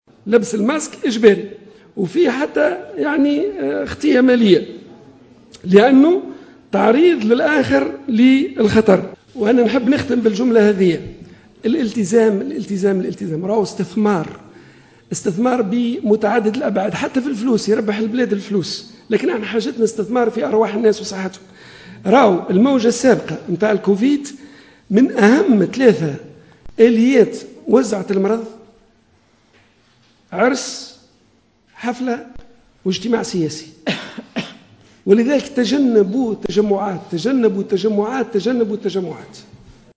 و قال المكي في تعليق مقتضب في ختام ندوة صحفية عُقدت اليوم بقصر الحكومة بالقصبة، إنّ هذه الآليات الثلاث، تتمثل في حفل زفاف، حفلة و اجتماع سياسي، مشددّا على ضرورة التزام المواطنين بقواعد الوقاية و تجنّب التجمعات، داعيا إياهم لارتداء الكمامات الطبية حتى لا يكونوا عرضة للعدوى أو لتسليط خطايا مالية ضدّ المخالفين، بسبب تعريض الآخر للخطر، حسب تعبيره.